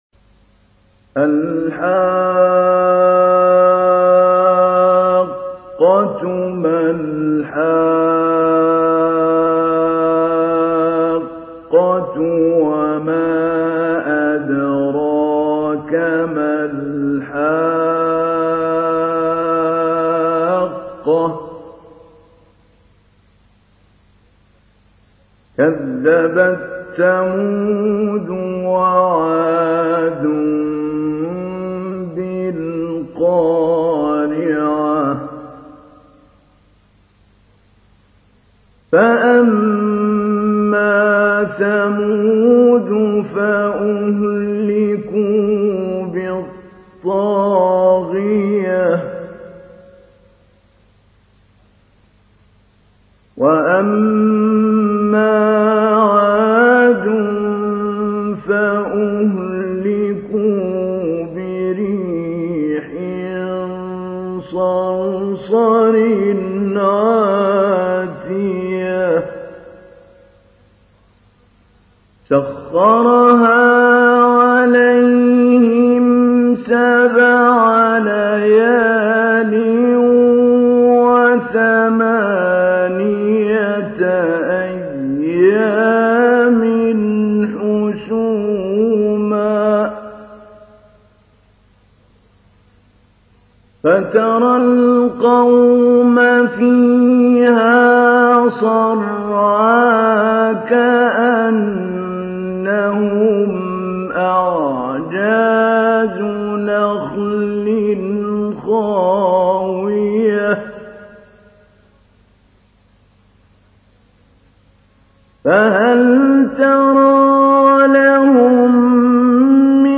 تحميل : 69. سورة الحاقة / القارئ محمود علي البنا / القرآن الكريم / موقع يا حسين